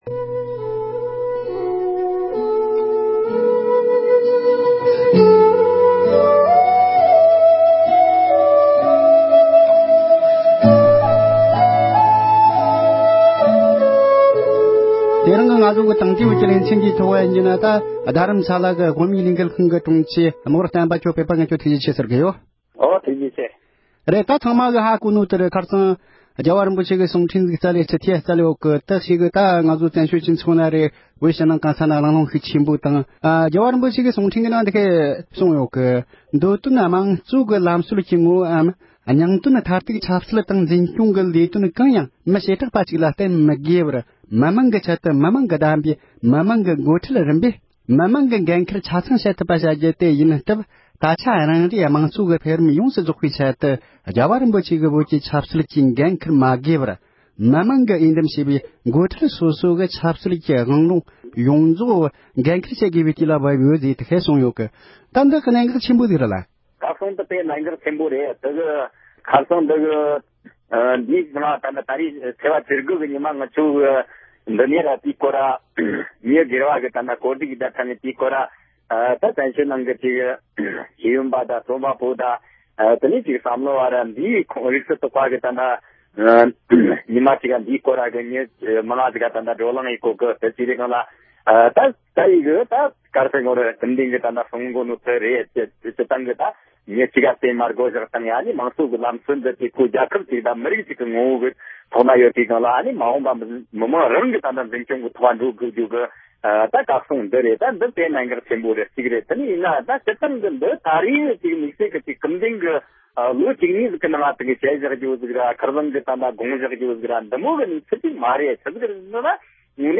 ༸གོང་ས་མཆོག་བགྲེས་ཡོལ་དུ་ཕེབས་རྒྱུའི་ཐད་བོད་མི་ཚོར་བསམ་ཚུལ་ཅི་ཡོད་པའི་སྐོར་གླེང་མོལ།